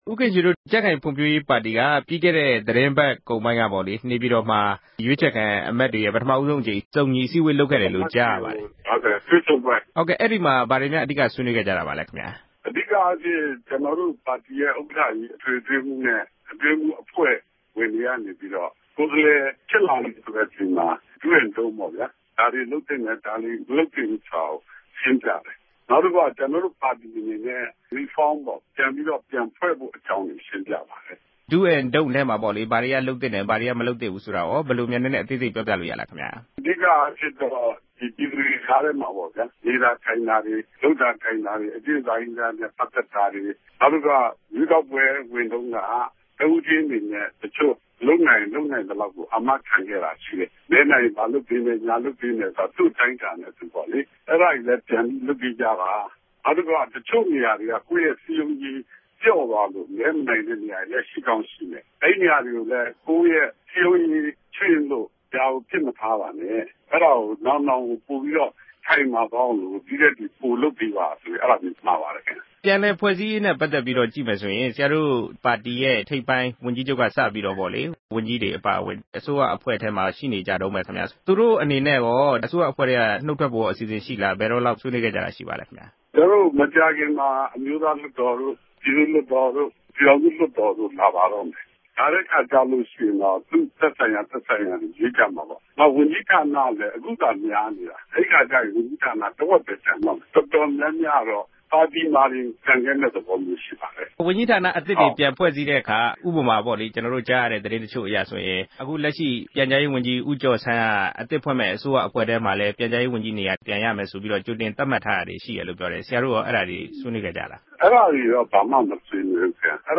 ဇေကမ္ဘာဦးခင်ရွှေနှင့် ဆက်သွယ်မေးမြန်းချက်